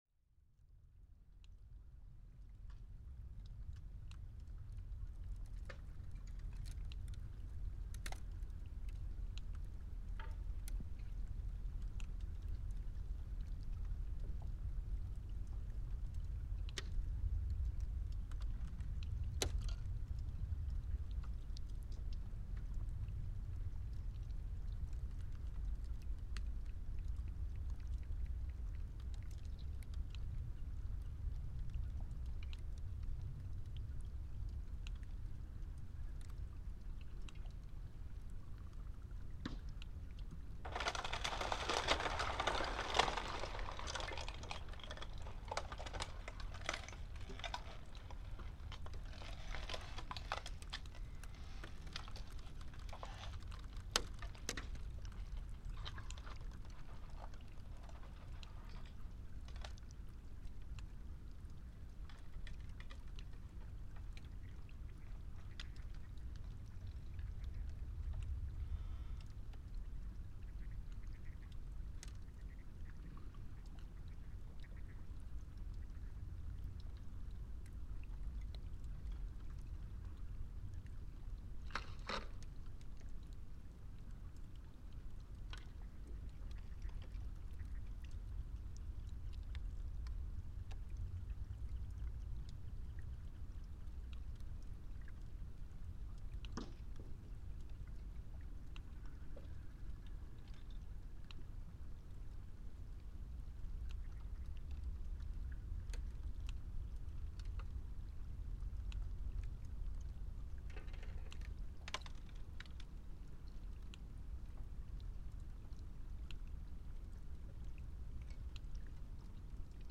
Melting icefall
After one of this overnight recording in 15th of May 2016 I decided to record near to a frozen pond close to the glacier’s toe. The time was around eight o´clock in the morning so the tourist traffic had not began to disturb the soundscape. The temperature was just below zero, but the morning sun was already melting the ice in the area. The soundscape was amazing.
t483_-melting_icefall.mp3